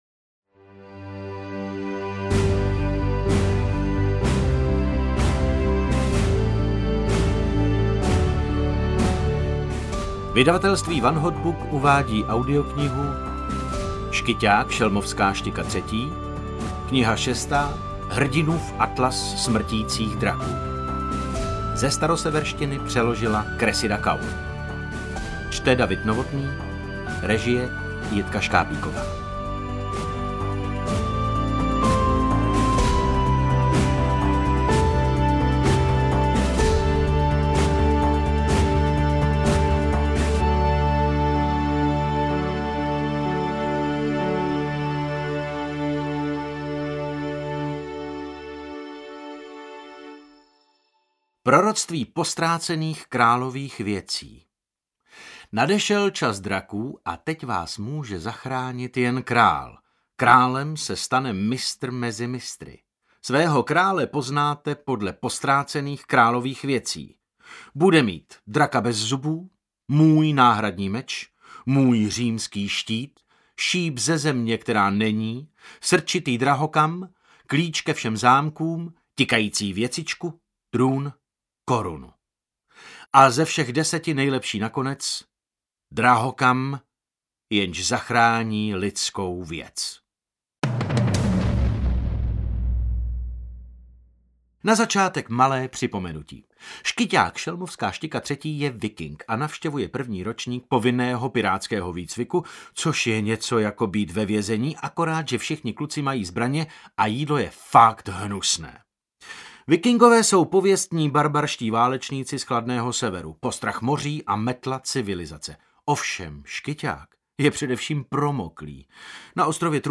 Interpret:  David Novotný